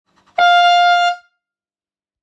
Ukázka zvuku vzduchového klaksonu/fanfáry s ruční pumpou
• Vytvořte atmosféru a buďte nepřehlédnutelní s touto výkonnou stlačenou vzduchovou houkačkou-fanfárou.